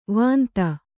♪ vānta